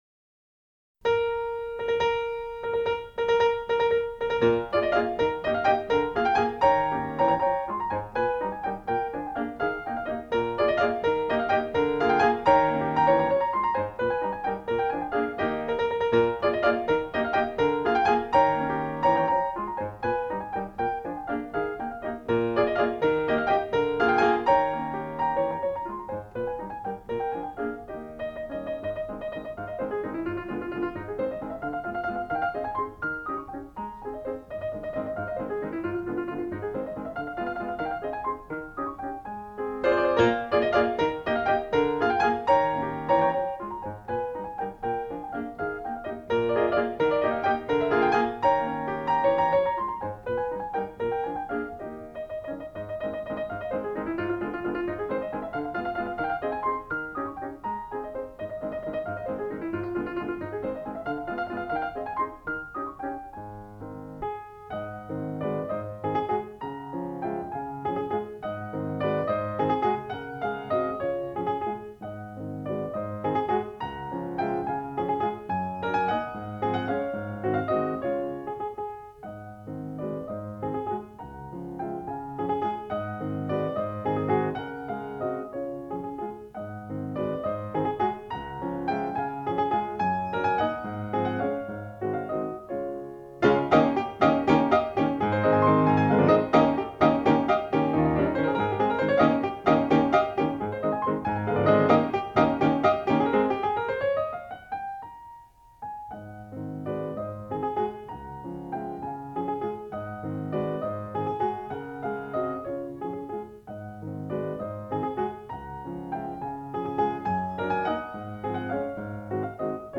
Op.18 Grande valse brilliante in E flat major - Arthur Rubinstein (1953)